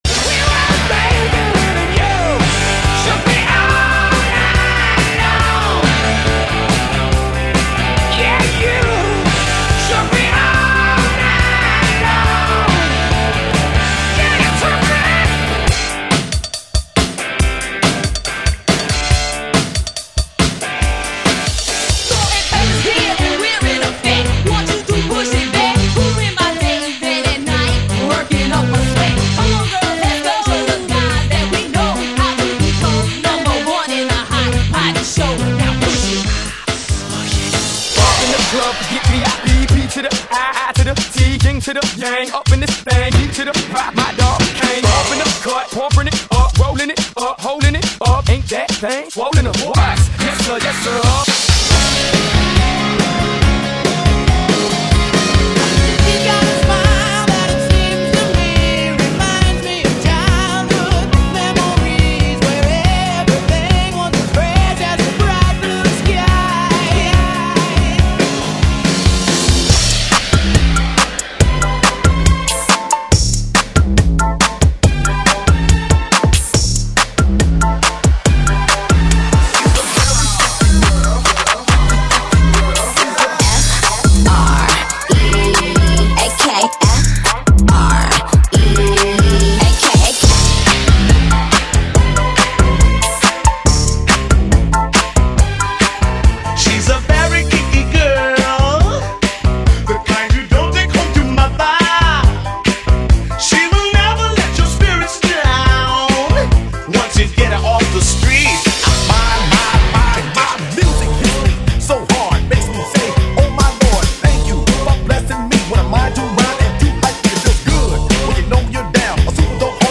Booty vs Funk vs Rock vs Party Throwbacks!